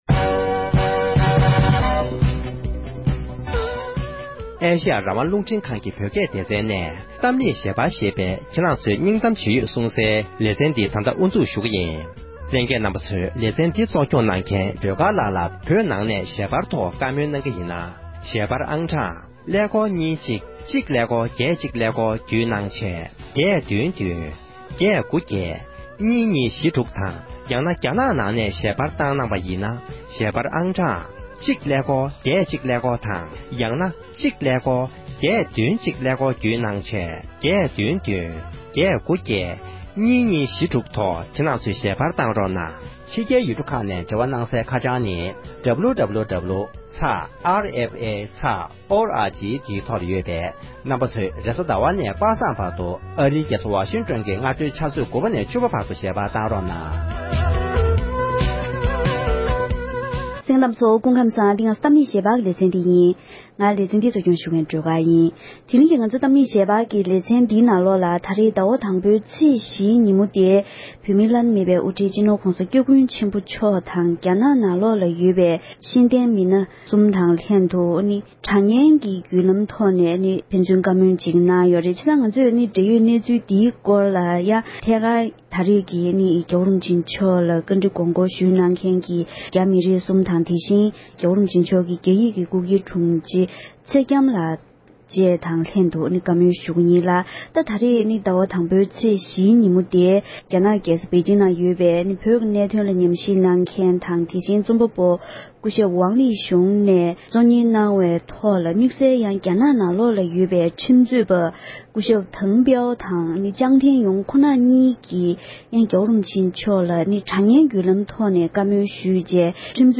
འབྲེལ་ཡོད་མི་སྣ་དང་བགྲོ་གླེང་ཞུས་པ་ཞིག